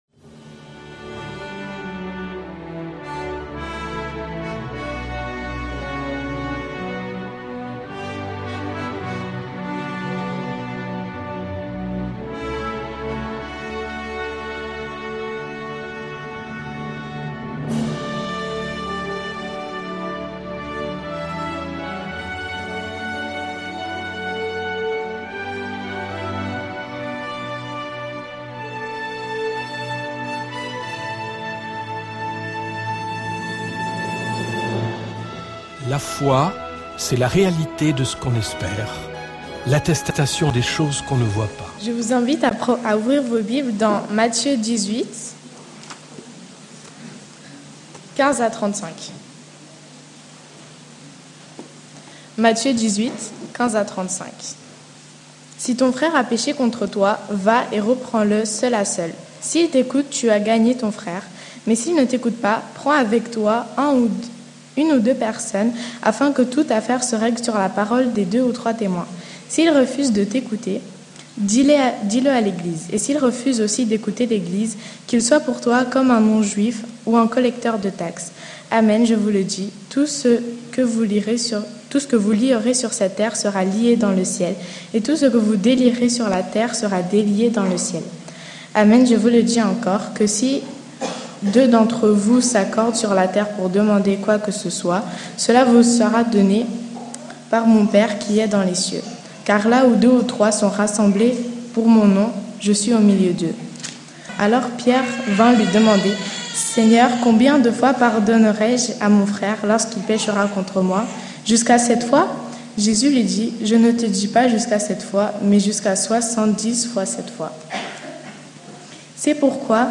14-CULTE_L_insolence_du_pardon.mp3